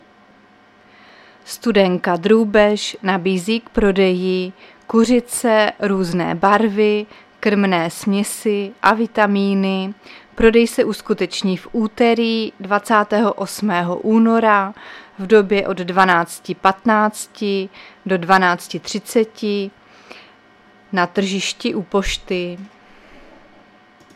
Záznam hlášení místního rozhlasu 27.2.2023